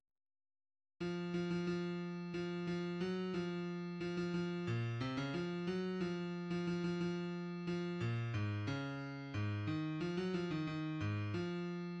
{ \clef bass \tempo 4=90 \key des \major \time 2/4 \set Score.currentBarNumber = #1 \bar "" r4 r8 f8 f16 f16 f4 f8 f ges f4 f16 f f8 bes, c16 des f8 ges f8. f16 f16 f16 f4 f8 bes, aes, des4 aes,8 ees f16 ges f ees ees8 aes, f4 } \addlyrics {\set fontSize = #-2 doggy doogy } \midi{}